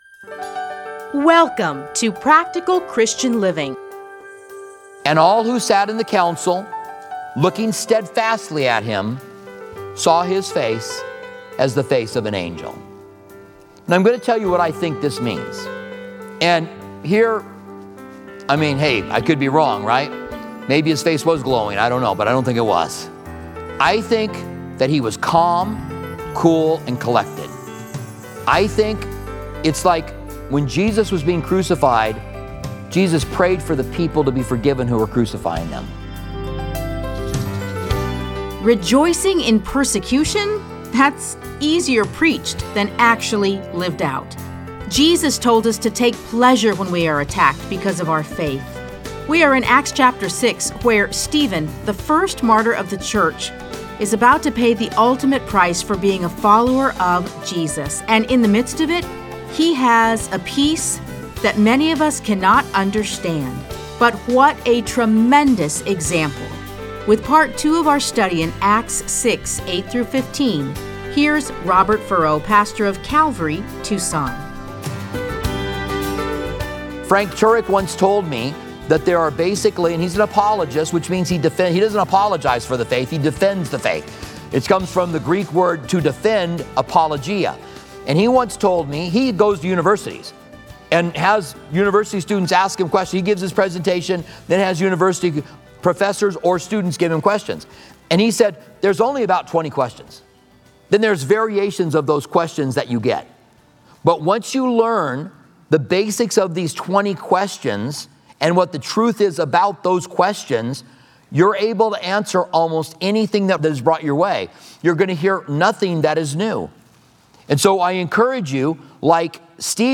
Listen to a teaching from Acts 6:8-15.